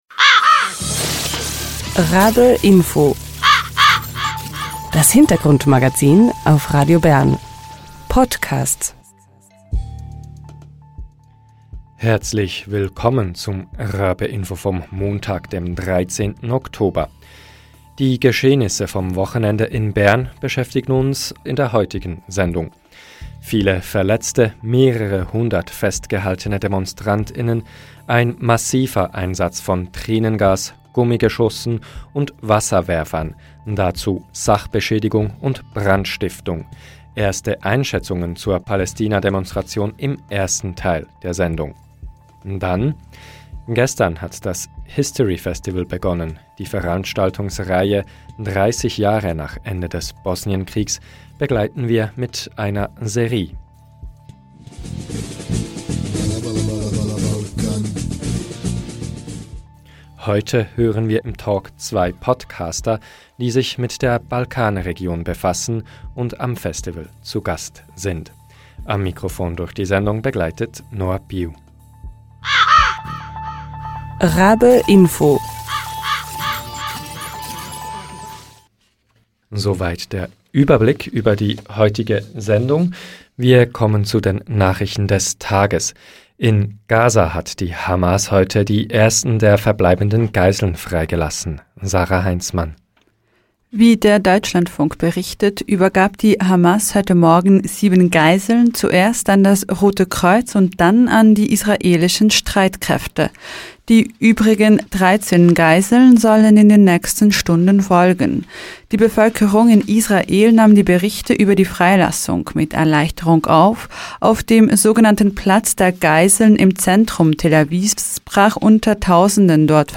Heute hören wir im Talk zwei Podcaster, die sich mit der Balkanregion befassen und am Festival zu Gast sind.